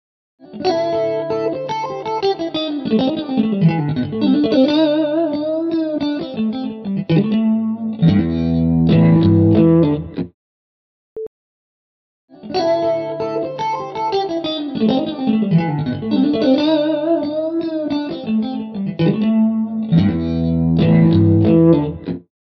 I’ve had a go with your Pseudo-stereo plug-in the results are very good: the inherent reverb makes it more realistic than my attempt at a pseudo-stereo effect.
A before-after example is attached with the default settings, (delay factor 30%, Effect mix 80%)